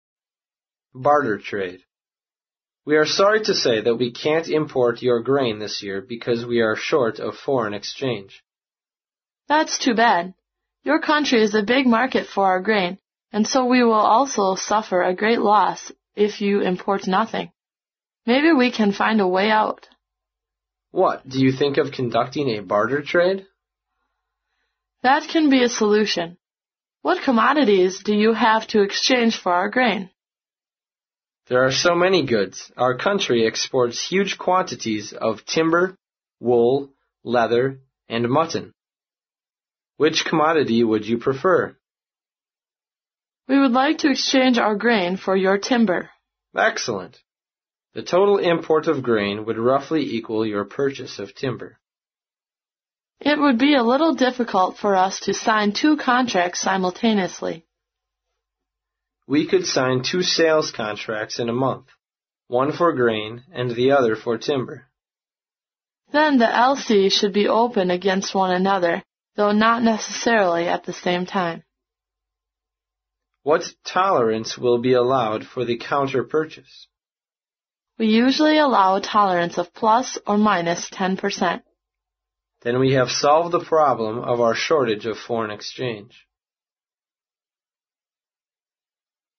在线英语听力室外贸英语话题王 第89期:易货贸易的听力文件下载,《外贸英语话题王》通过经典的英语口语对话内容，学习外贸英语知识，积累外贸英语词汇，潜移默化中培养英语语感。